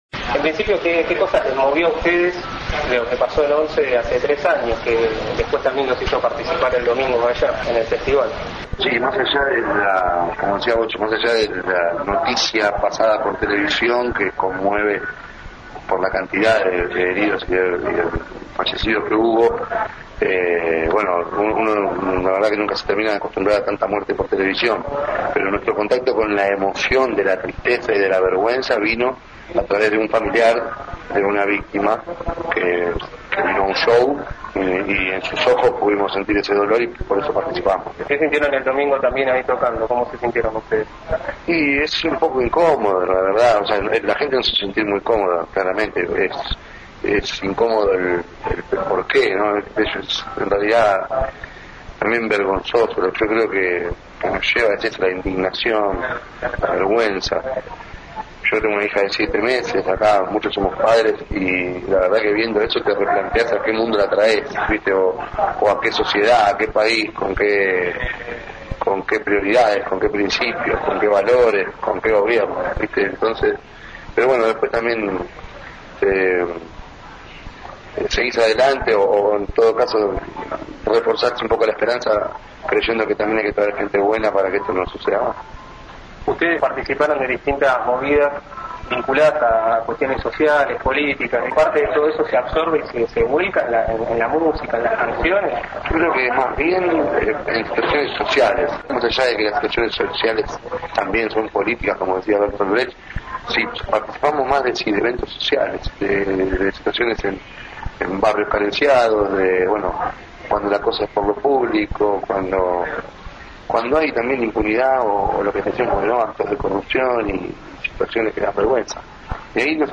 Entrevista en el programa ABRAN PASO